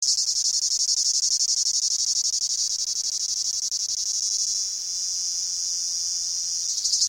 b.) eine einzelne "Cigale commune", aufgenommen in Kroatien